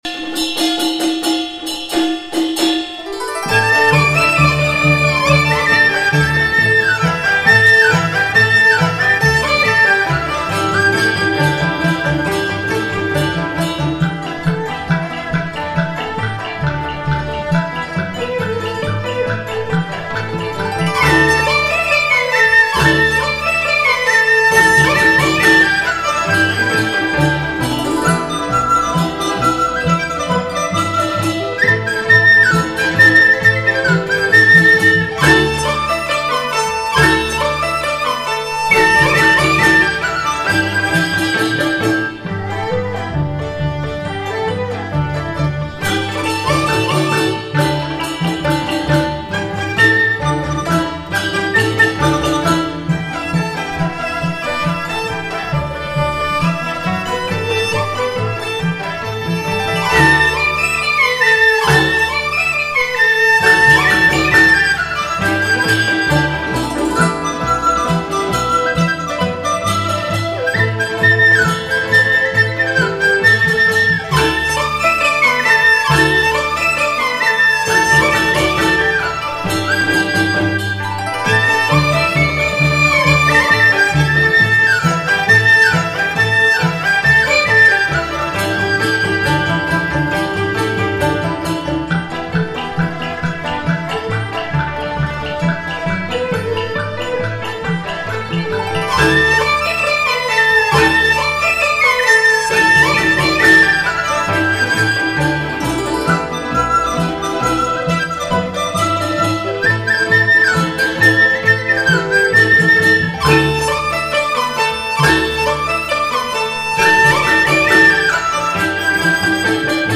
音乐类型: 民乐
中国人过年过节、家中有喜事的时候讲究欢天喜地，整张专辑的多数作品是根据中国民歌改编的，喜庆、欢快、热闹，节日气氛很浓。
而且由中国民族管弦乐队演奏，尤其是笛子、二胡等的鲜明演奏，民族特色非常浓郁。